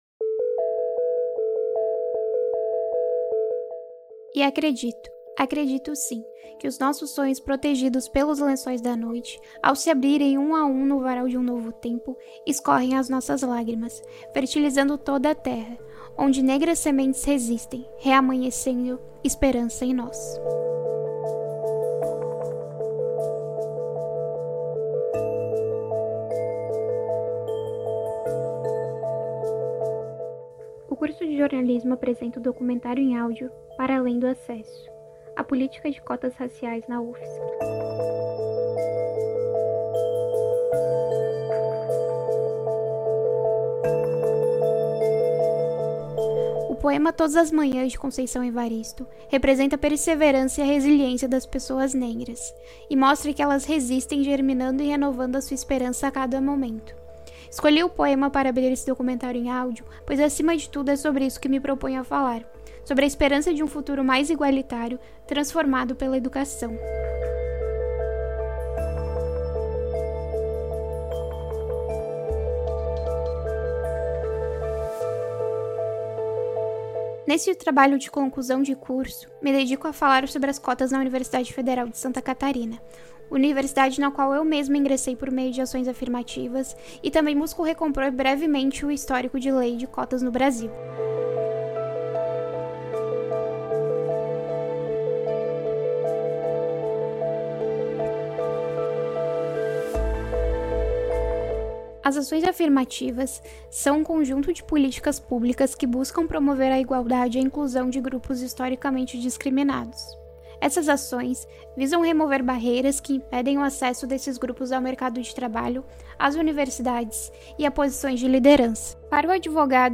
Documentário em Audio